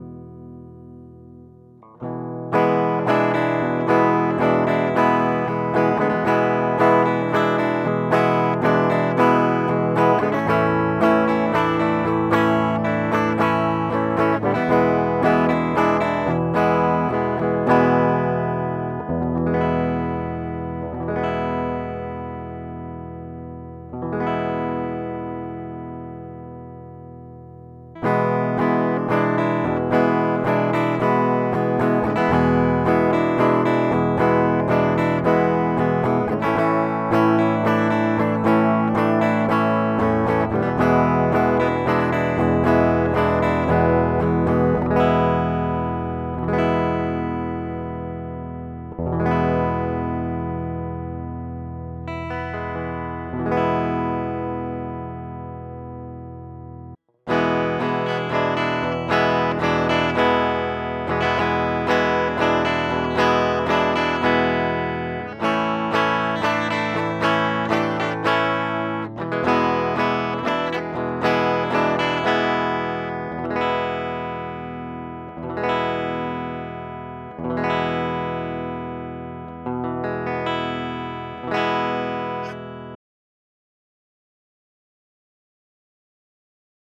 Как звучат предусилители. Может кому-то интересно (нив 1073, Bae 312a, пред карты Apollo Twin)/ Запись - Apollo Twin Мое мнение - нив самый сбалансированный, бае - мутноват и низа больше, аполло - ну это жесть конечно...)))